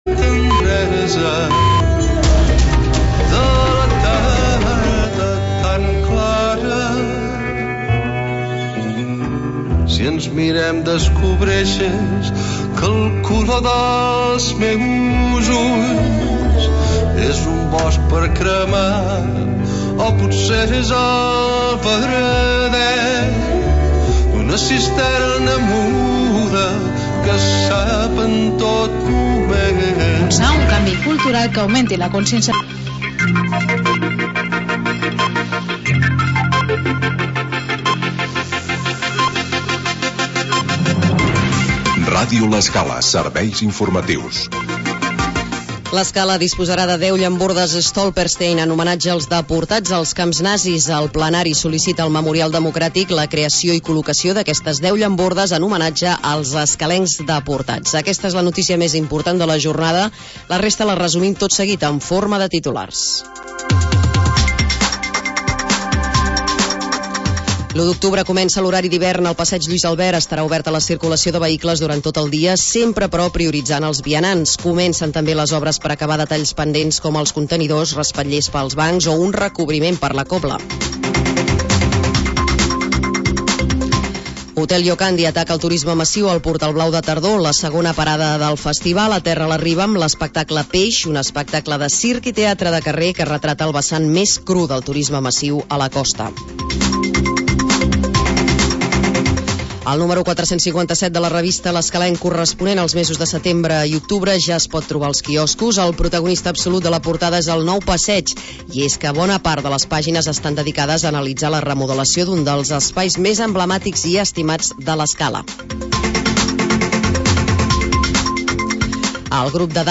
Noticiari d'informació local